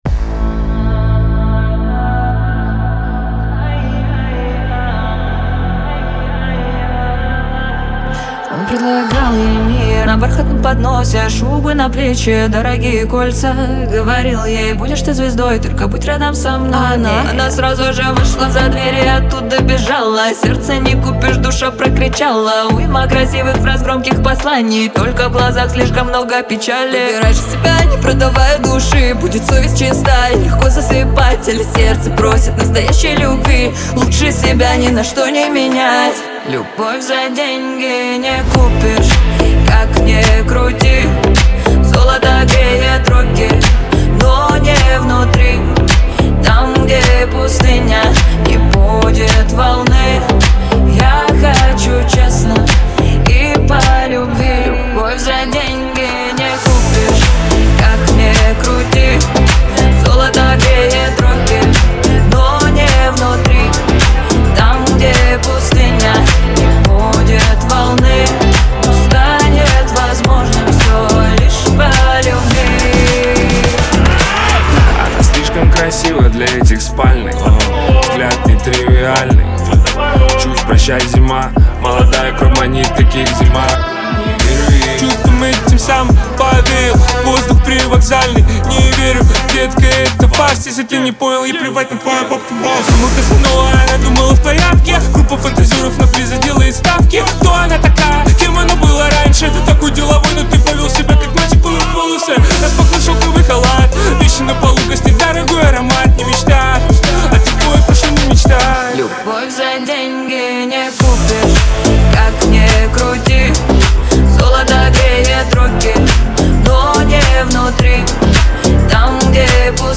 pop
диско , эстрада
дуэт